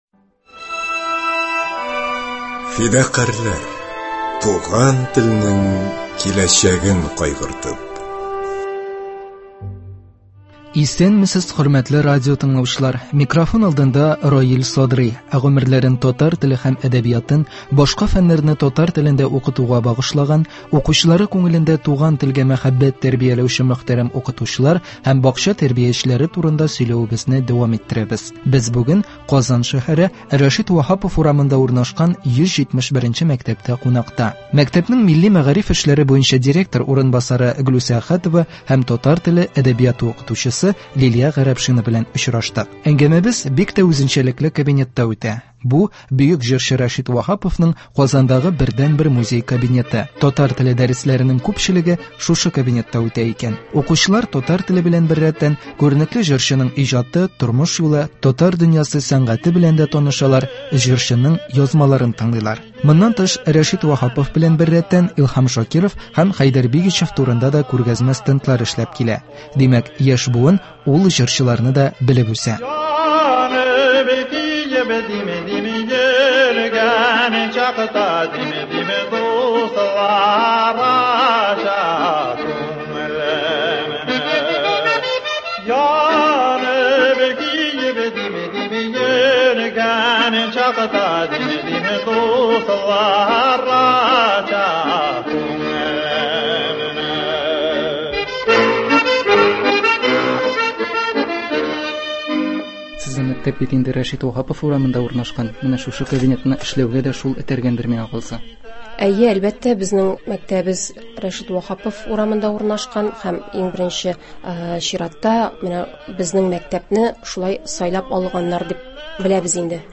Әңгәмәбез бик тә үзенчәлекле кабинетта үтә.